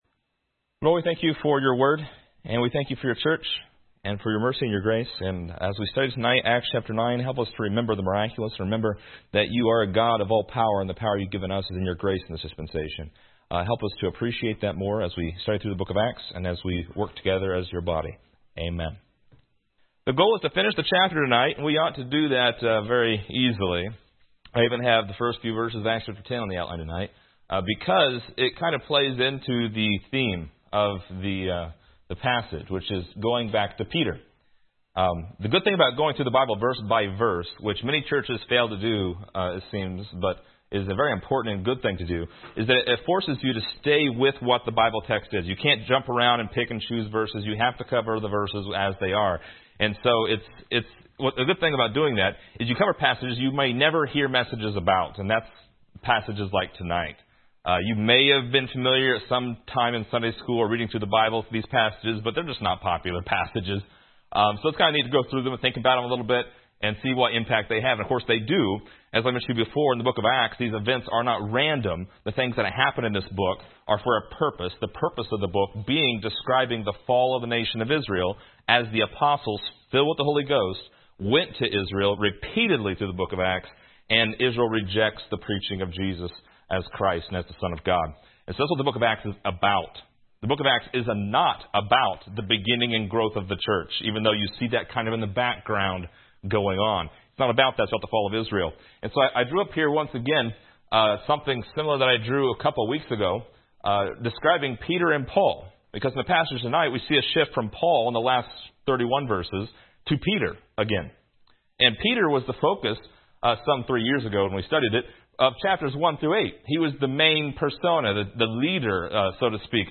This lesson is part 25 in a verse by verse study through Acts titled: Peter’s Three Miraculous Visits.